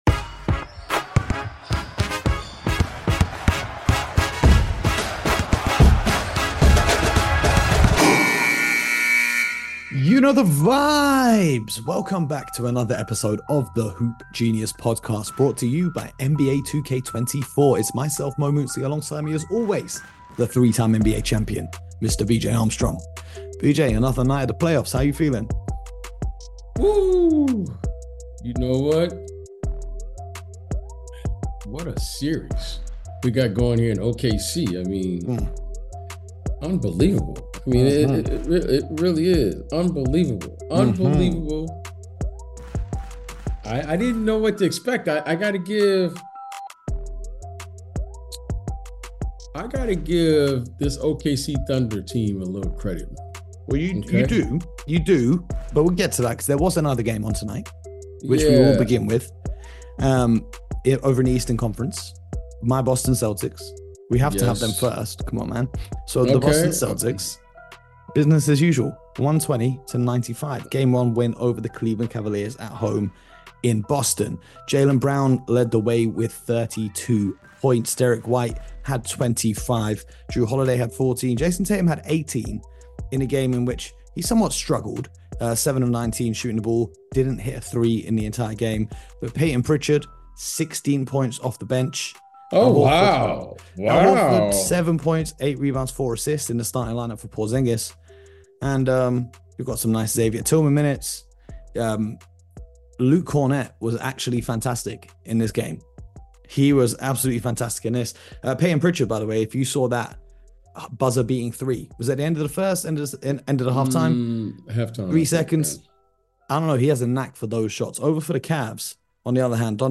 debate and discuss the action from Game 1 between the Boston Celtics & Cleveland Cavaliers, and Game 1 between the Oklahoma City Thunder & Dallas Mavericks! They also previewed Game 2 of tonight's series' between the New York Knicks & Indiana Pacers, and the impact of Mitchell Robinson's injury.